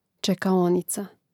čekaònica čekaonica im. ž. (G čekaònicē, DL čekaònici, A čekaònicu, I čekaònicōm; mn.